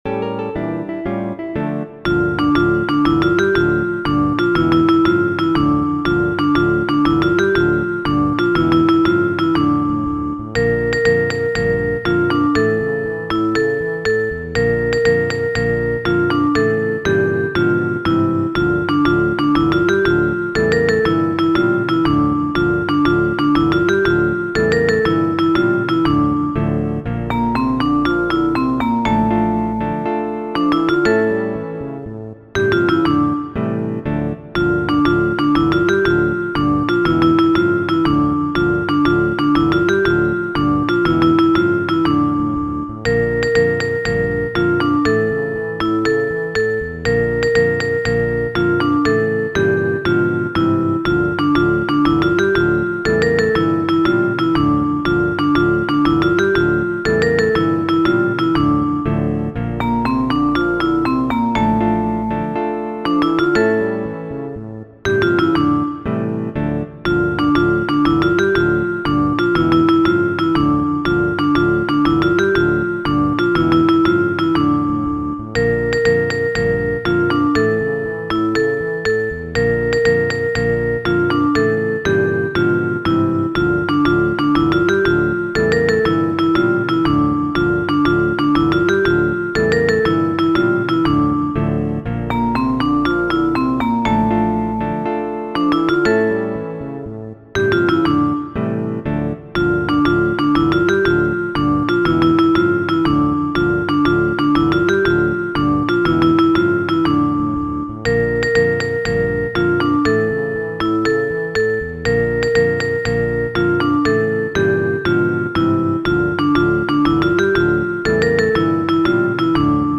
Muziko:
kanto